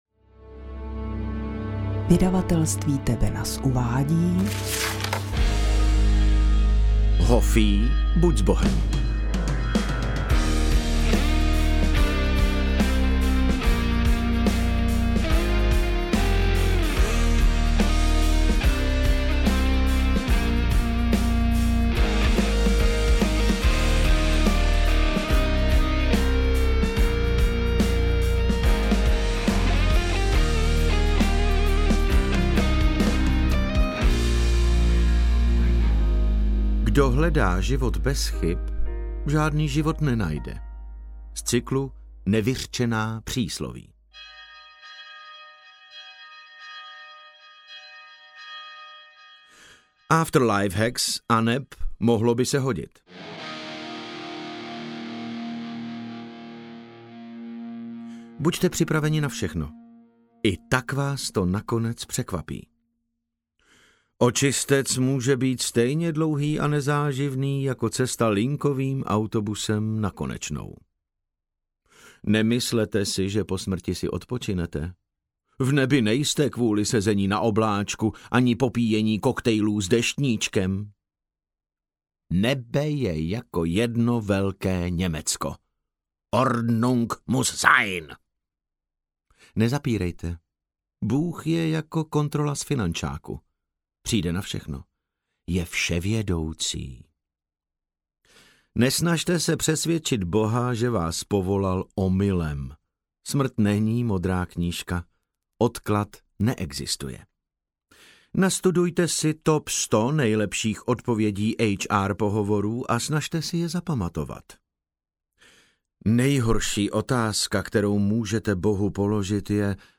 Interpret:  David Novotný
AudioKniha ke stažení, 24 x mp3, délka 6 hod. 57 min., velikost 581,0 MB, česky